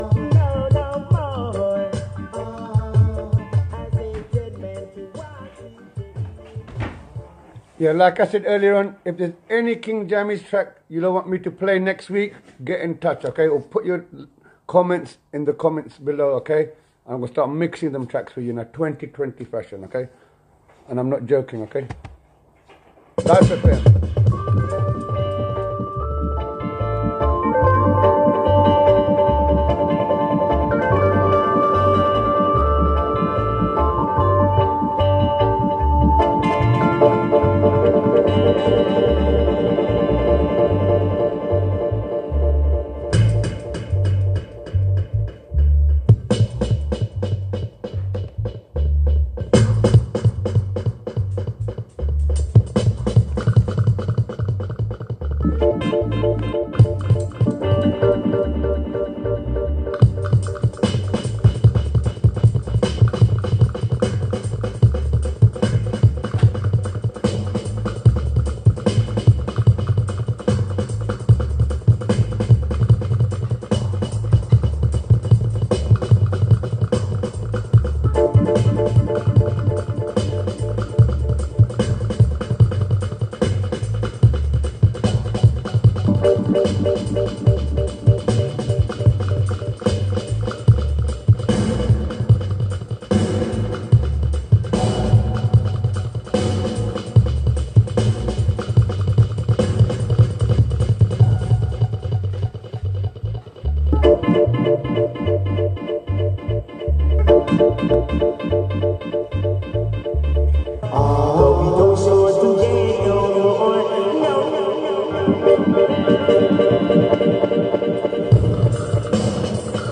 Gaps were edited out.